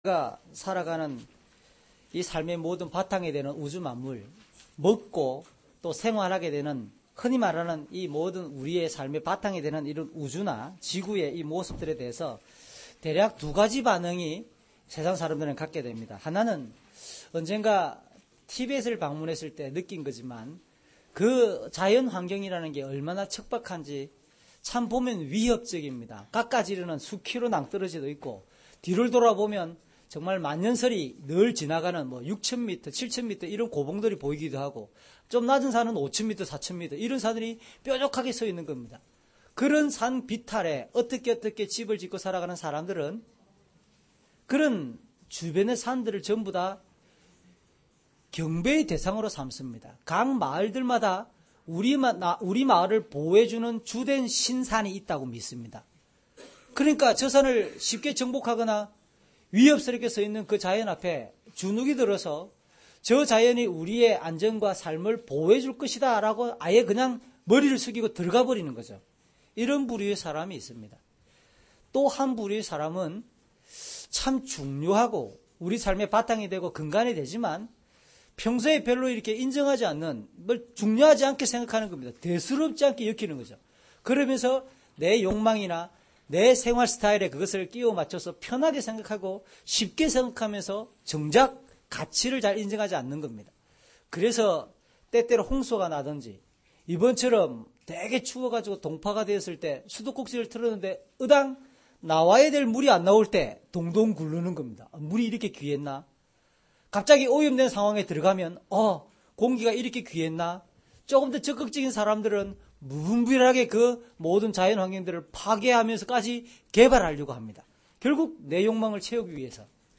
주일설교 - 11년 02월 06일 "삶의 모든 바탕은 선물입니다."(출7:14-25)